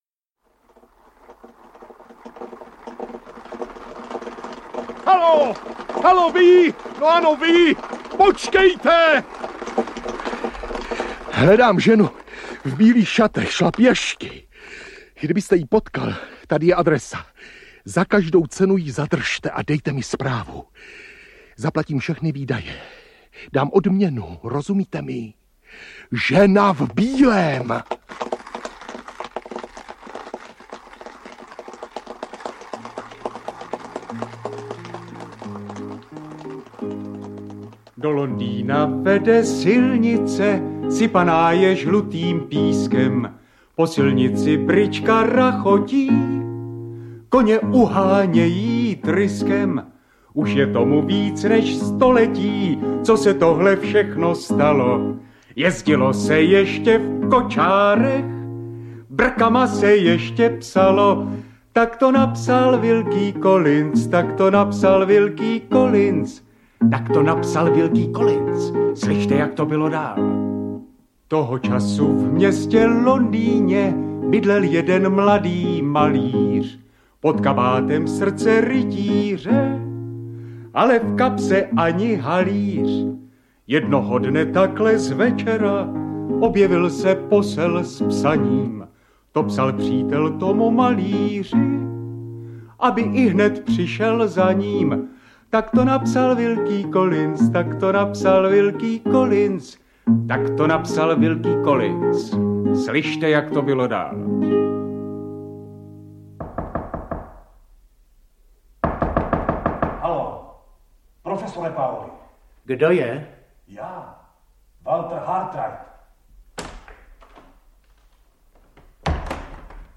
Žena v bílém audiokniha
Celý popis Rok vydání 2018 Audio kniha Zkrácená verze Ukázka z knihy 269 Kč Koupit Ihned k poslechu – MP3 ke stažení Potřebujete pomoct s výběrem?